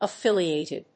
音節af・fíl・i・àt・ed 発音記号・読み方
/‐ṭɪd(米国英語), ʌˈfɪli:ˌeɪtɪd(英国英語)/